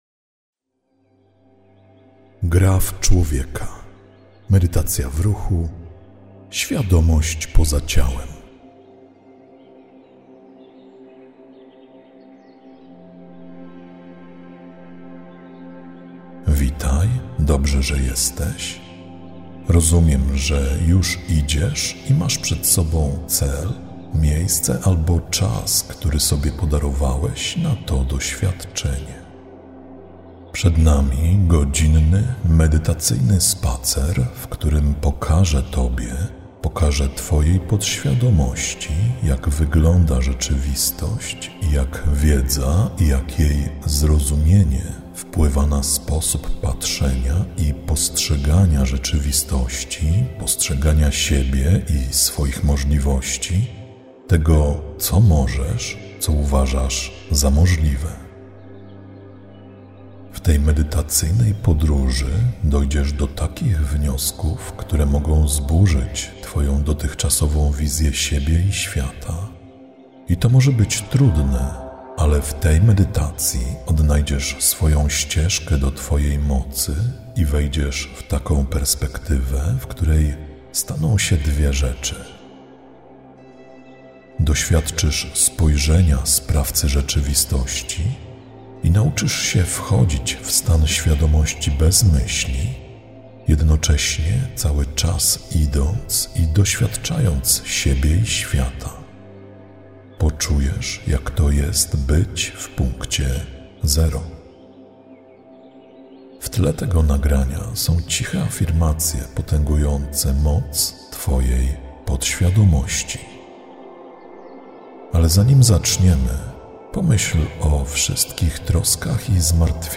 Zawiera lektora: Tak
Spacerujesz słuchając mojego głosu.